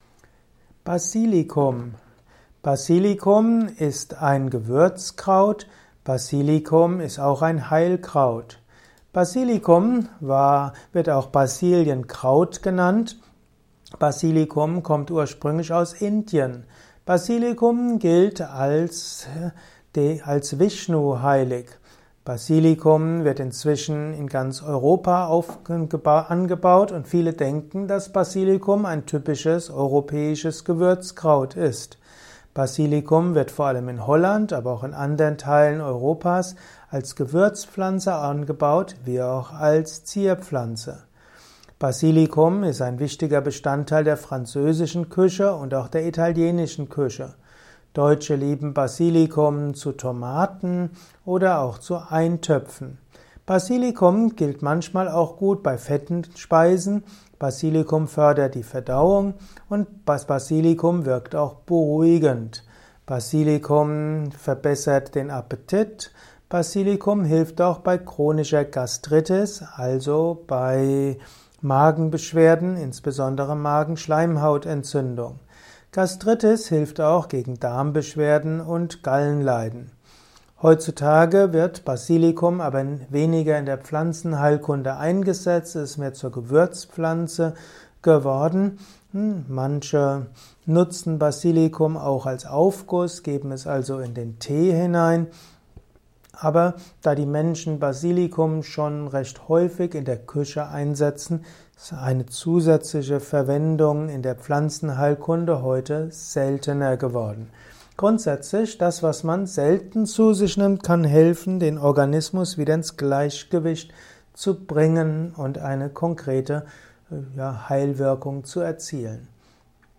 Kompakte Informationen zum Basilikum-Öl in diesem Kurzvortrag